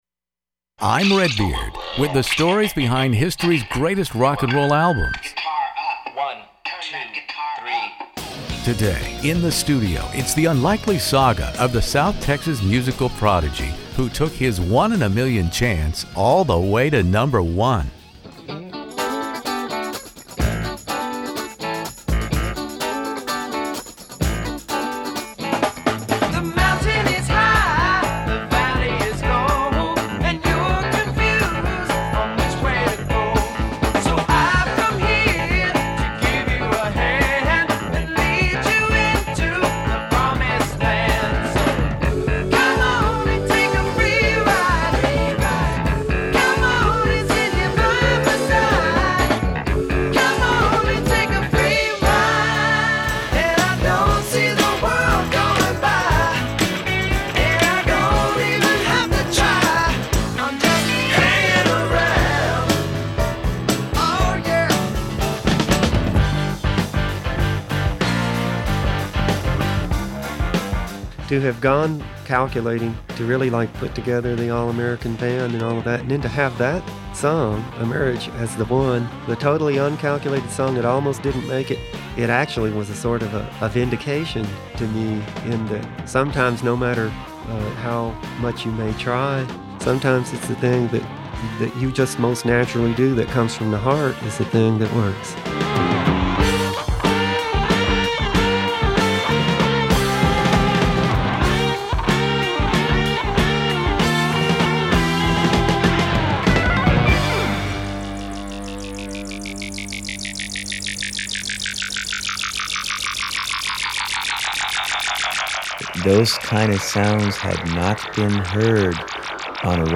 Edgar Winter They Only Come Out at Night interview In the Studio